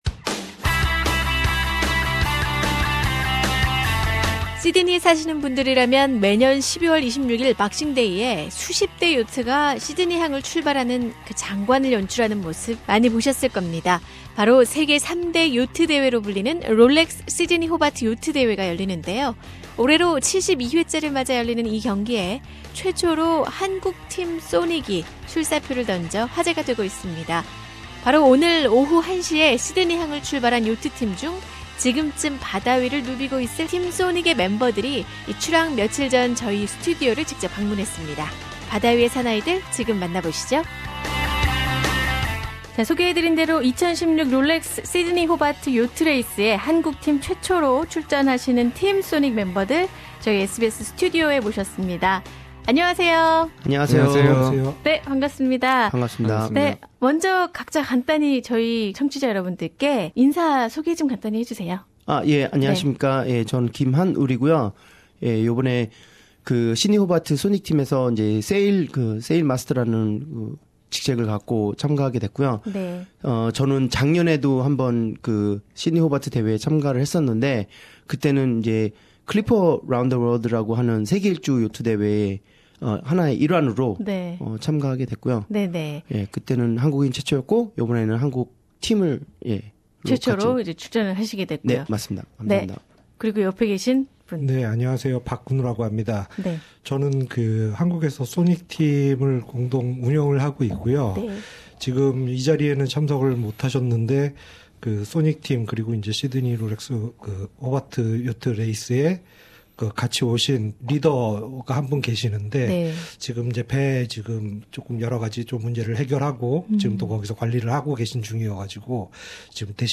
오늘(12월 26일) 오후 한시에 시드니 항을 출발해 바다 위를 누비고 있을 팀 소닉의 멤버들이 출항 며칠 전 SBS 스튜디오를 직접 방문했습니다.